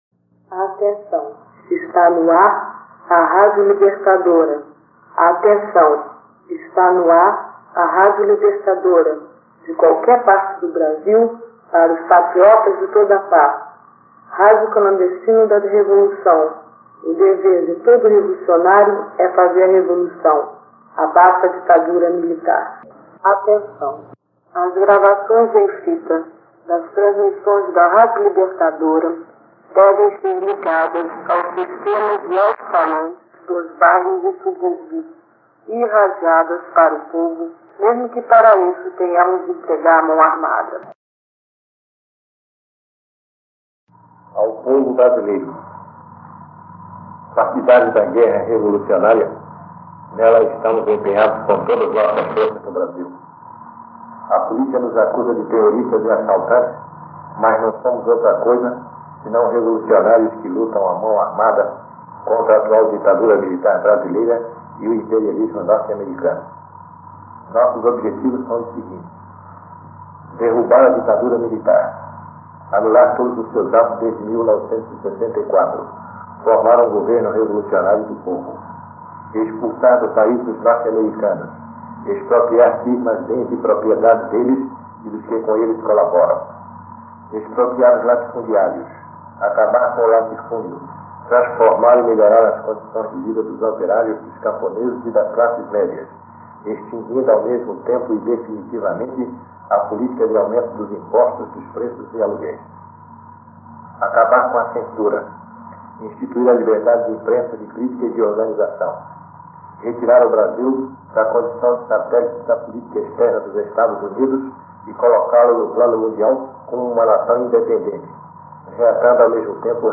Gravações em Fita da Rádio Libertadora